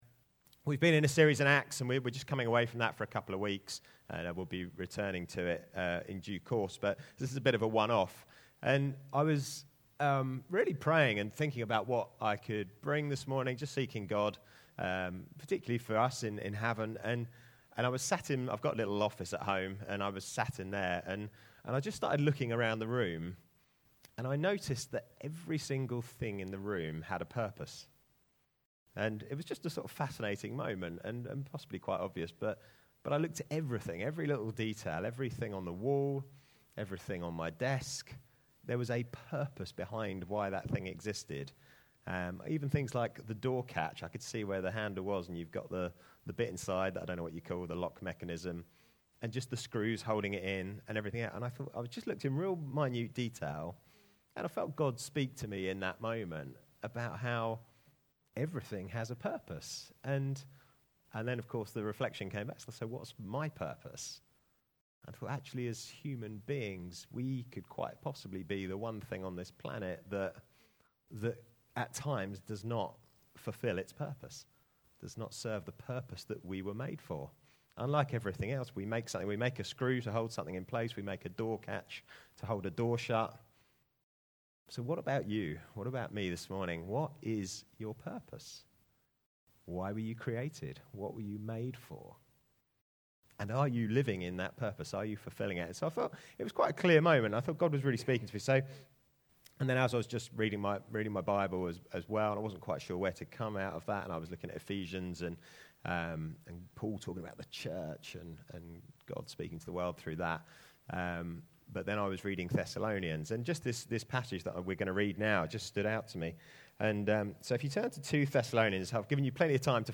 In this sermon, we explore how everything in creation was made for God’s glory. We’ll discover how living for God’s glory transforms our relationships, work, and daily choices, bringing us the joy and peace we were designed to experience.